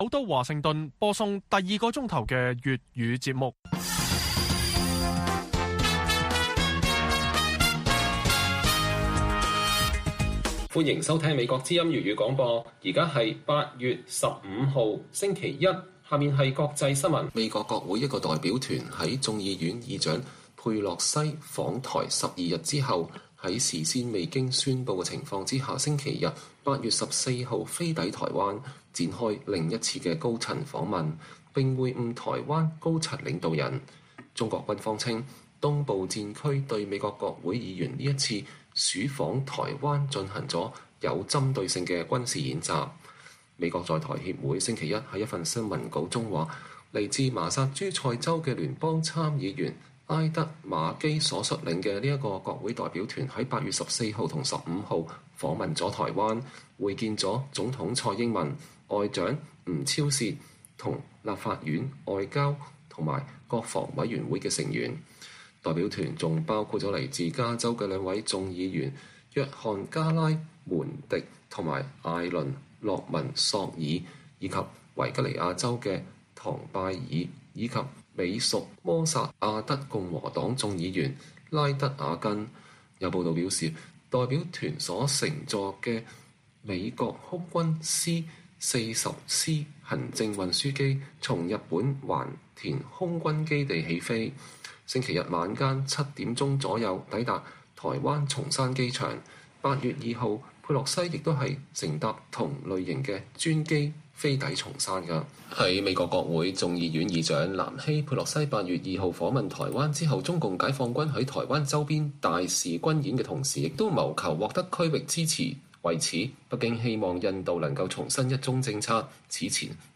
粵語新聞 晚上10-11點: 美國之音獨家專訪台灣外長吳釗燮